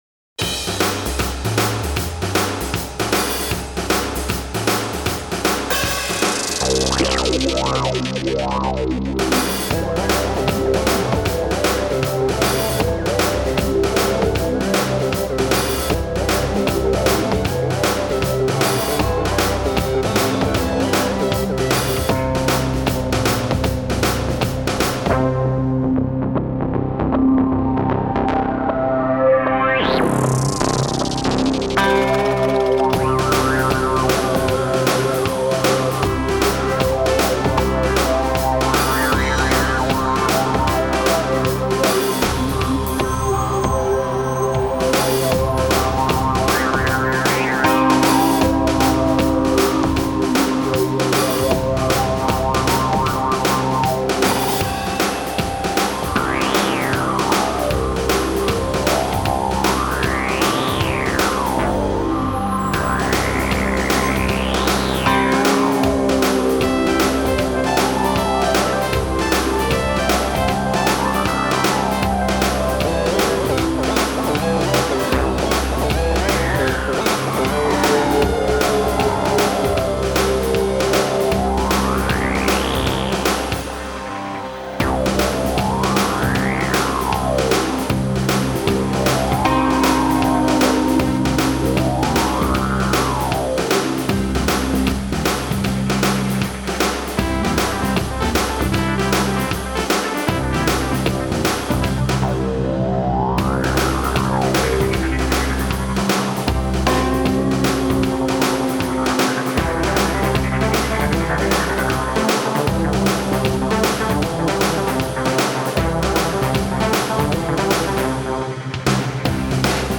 Drum beat driven Rock track for arcade and reality TV.